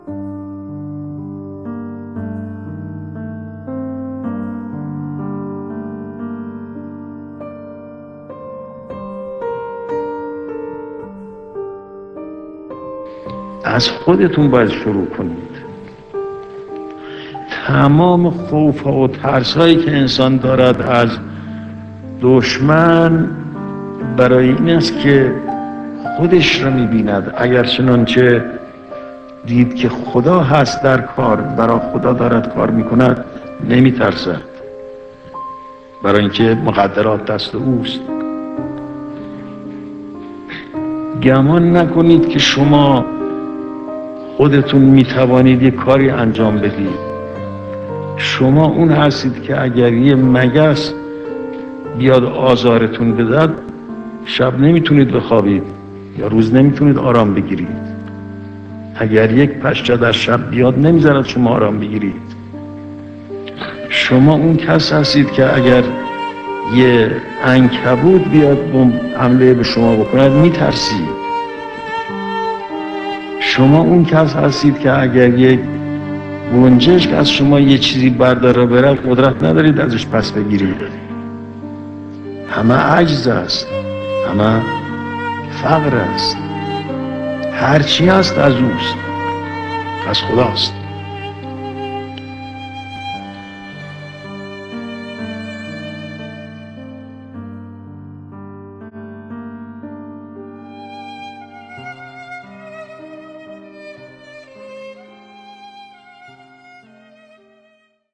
بنیانگذار کبیر انقلاب اسلامی، امام خمینی(ره) به عنوان یکی از مراجع بزرگ تقلید شیعیان سخنان مبسوطی در خصوص تهذیب نفس داشتند. ایشان در یکی از سخنرانی‌های خود با تأکید بر اینکه باید از خودتان شروع کنید، گفت: تمام خوف‌ها و ترس‌هایی که انسان از دشمن دارد، برای این است که خودش را می‌بیند.